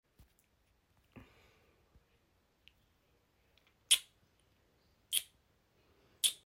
ASMR mouth sounds sound effects free download